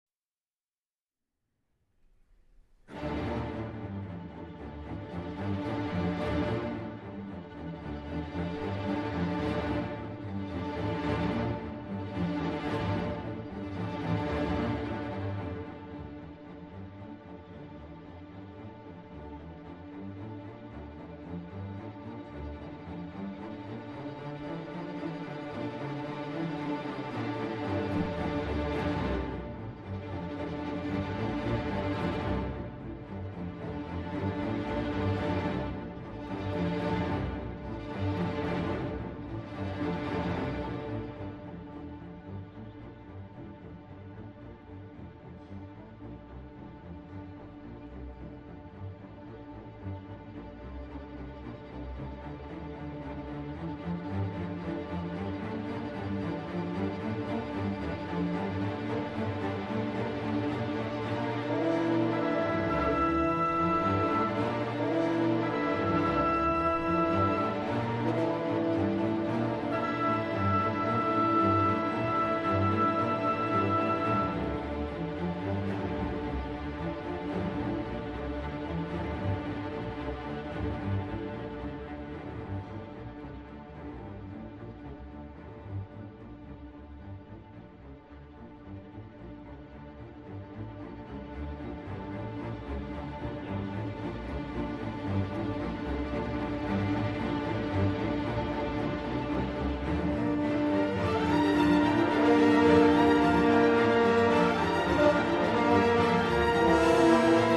古典音樂